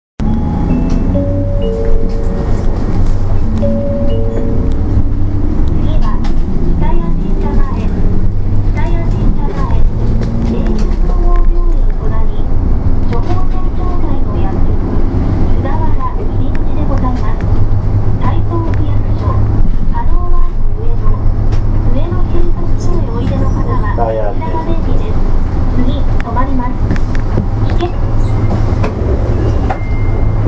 音声合成装置   レゾナント・システムズ(旧ネプチューン)
車内放送   発車時に「発車します、おつかまりください」と流れます。